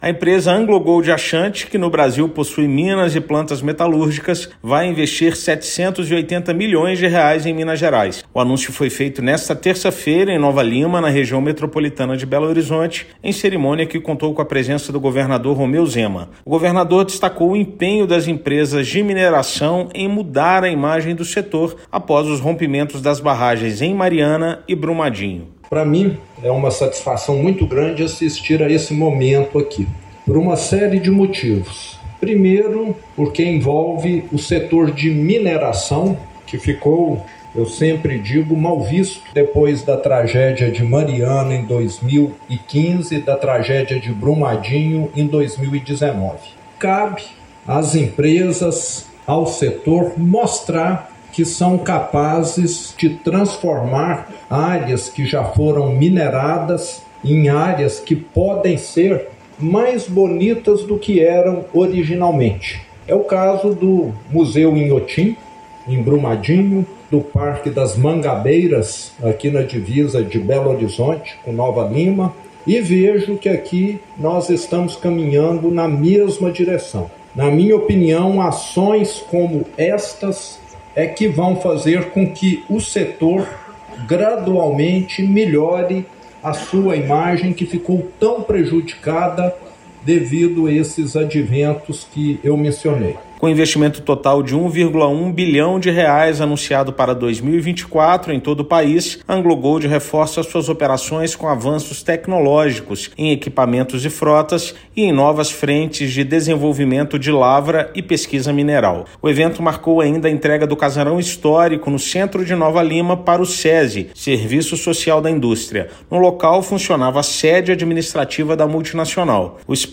Governador Romeu Zema salientou que a mineração pode ser realizada de forma responsável em prol do desenvolvimento econômico e social da população mineira. Ouça matéria de rádio.